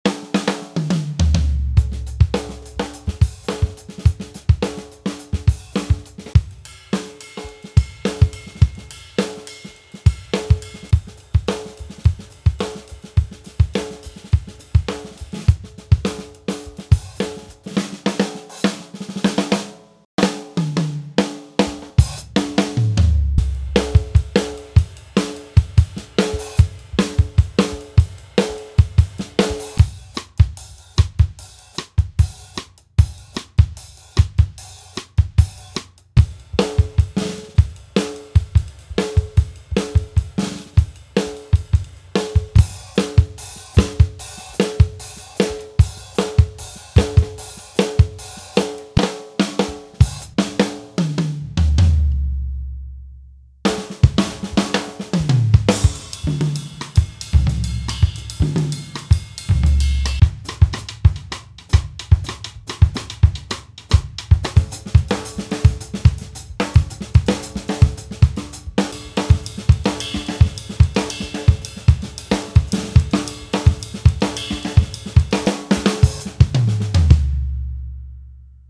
This pack features a carefully curated collection of drum beats, fills, and percussion one-shots, each recorded to deliver the rich, dynamic sound of live acoustic drums.
35 Drum Beats: Explore an array of drum beats that blend the infectious grooves of funk with the intricate rhythms of jazz.
44 Drum Fills: Add excitement and variation to your music with these dynamic drum fills.
Each element is meticulously recorded and expertly played to capture the organic feel and dynamic expression of a live drummer.
Funky-Jazz-Acoustic-Drums-Vol-1.mp3